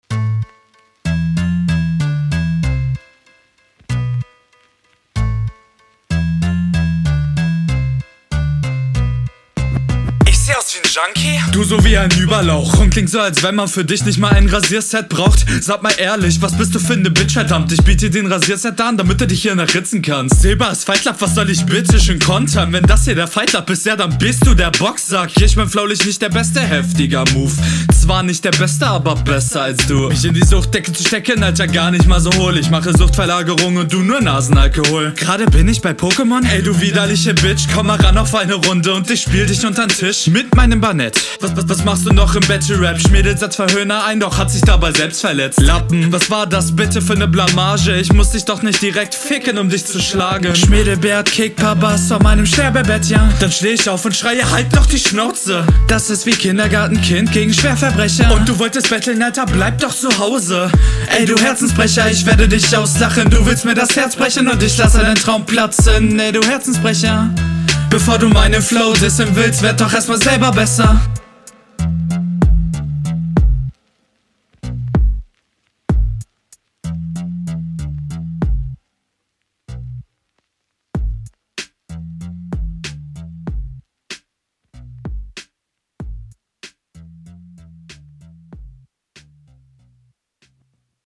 gut gekontert, stimme etwas zu laut ....